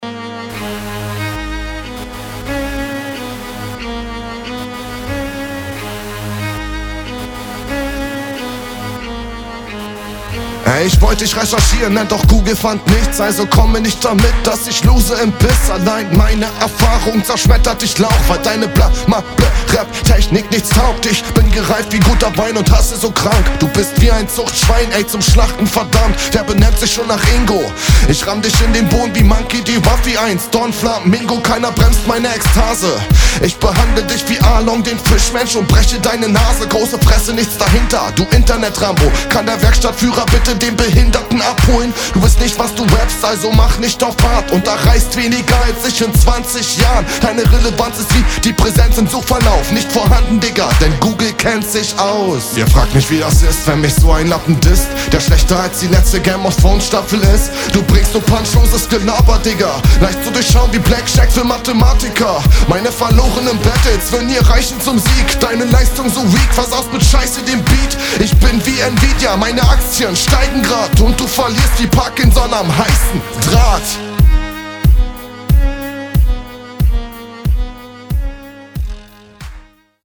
teilweise echt unsicher :o